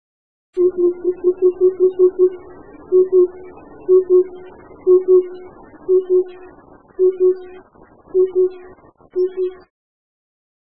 2125e「鳥の鳴声」
〔ツツドリ〕フォン，フォン，フォン（ポポ，ポポ）／落葉樹林や平地に棲息，普通・